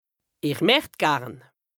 L’alsacien regroupe plusieurs variantes dialectales du Nord au Sud de l’Alsace.
Nous avons tenté d’être représentatifs de cette diversité linguistique en proposant différentes variantes d’alsacien pour chaque lexique, à l’écrit et à l’oral.